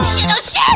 Worms speechbanks
Takecover.wav